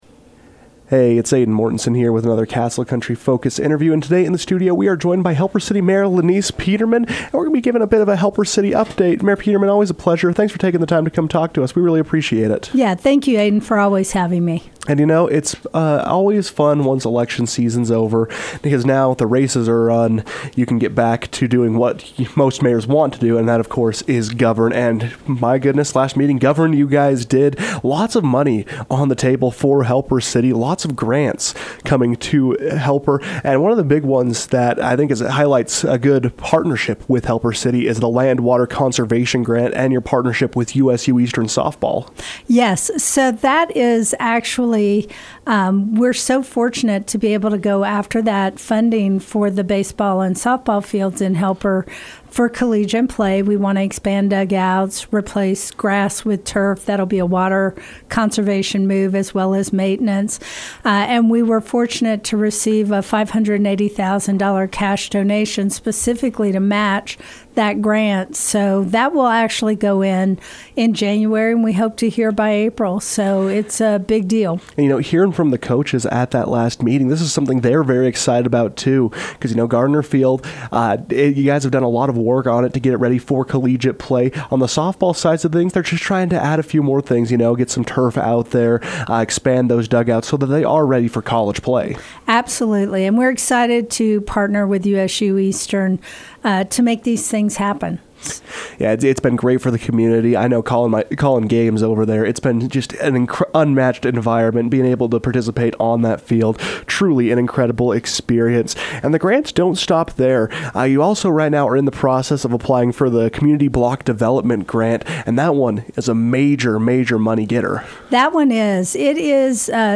Peterman joined the KOAL newsroom to discuss some of the funds coming to the city, while also giving a look at what is required after acceptance.